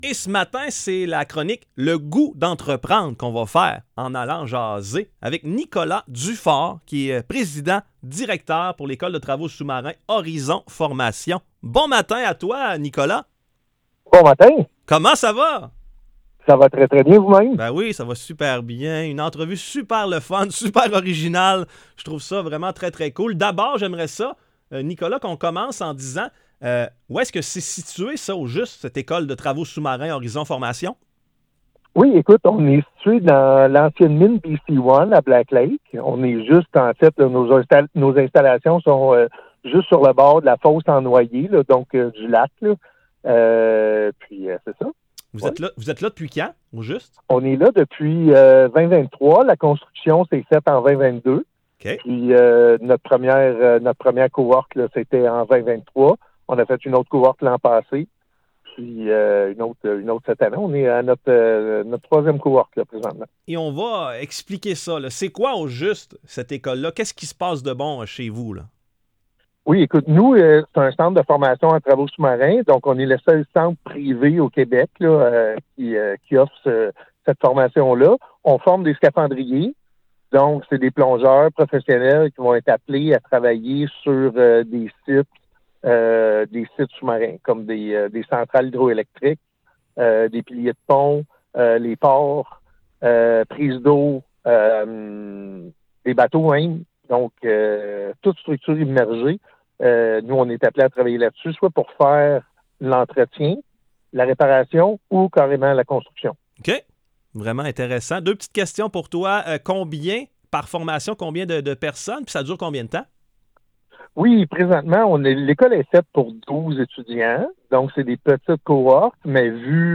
Chroniques radio
Les chroniques radio de la MRC des Appalaches sont diffusées à la station de radio VIVA 105,5, les mercredis chaque deux semaines, vers 8h00.